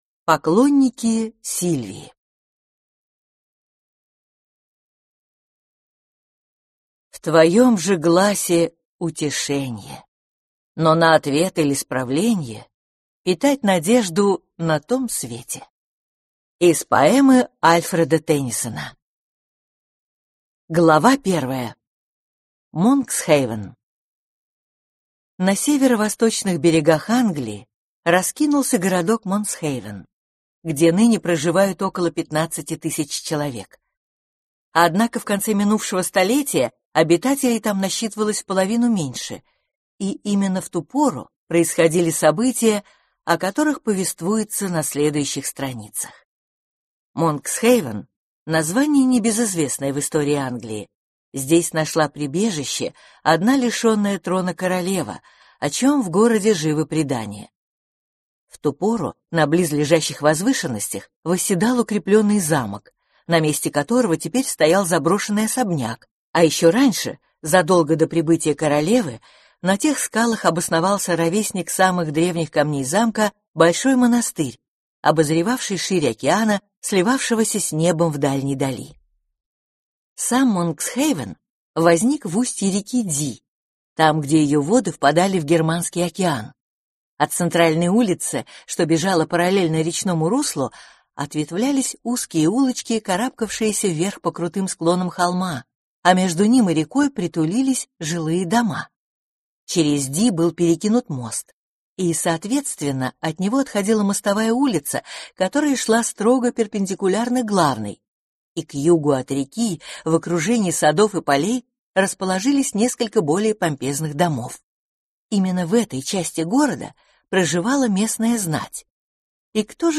Аудиокнига Поклонники Сильвии | Библиотека аудиокниг